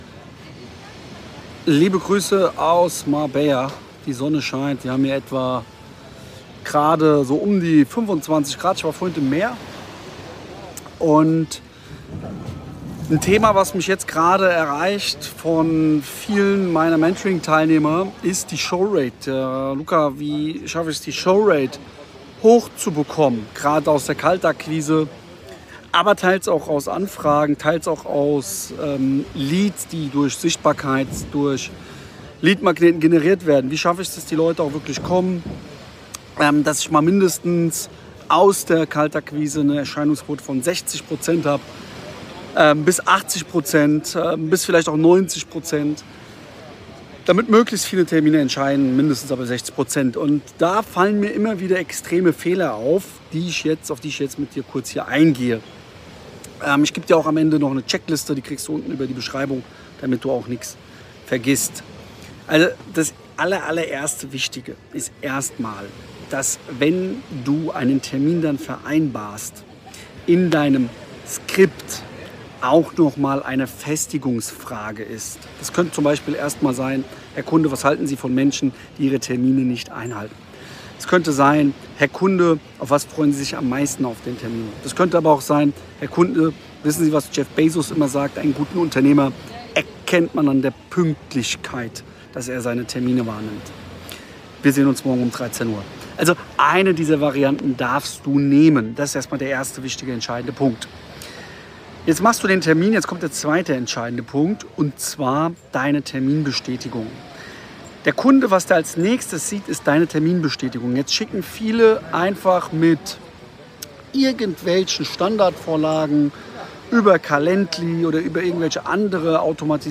Diese Episode des Podcasts ist ein Audiomitschnitt aus dem YouTube Video https